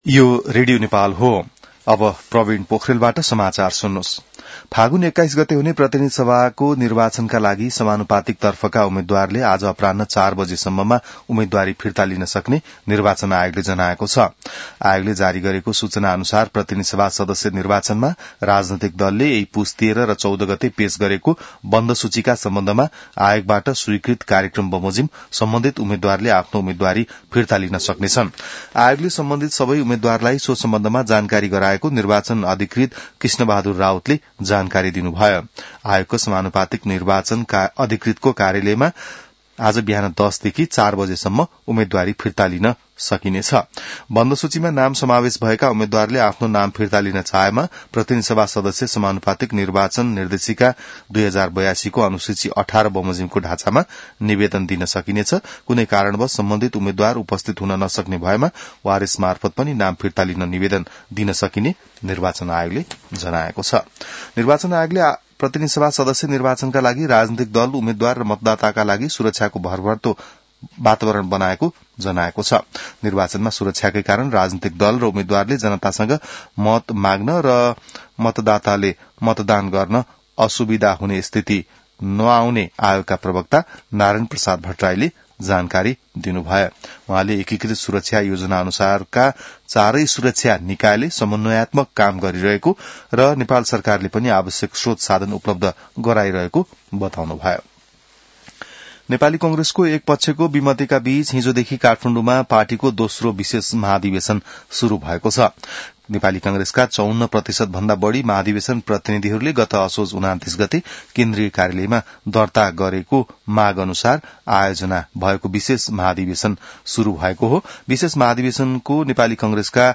बिहान ६ बजेको नेपाली समाचार : २८ पुष , २०८२